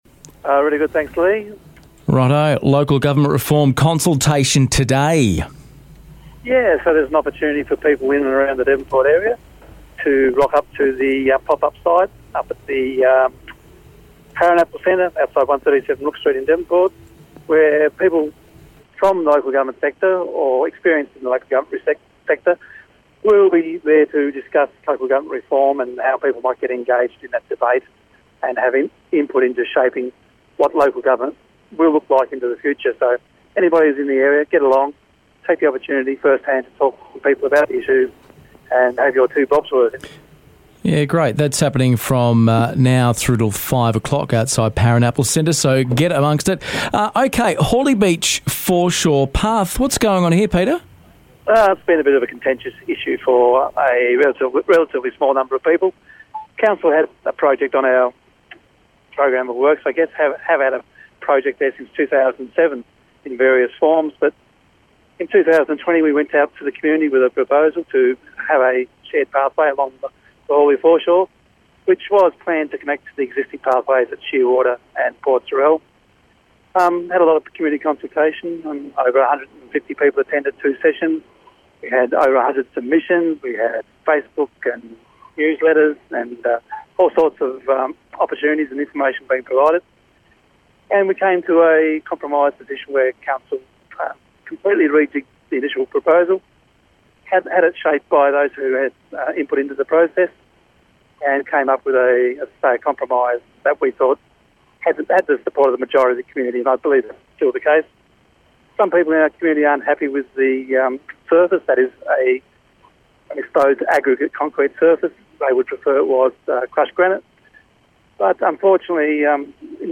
Mayor Peter Freshney jumps on air to update the coast on Latrobe movements.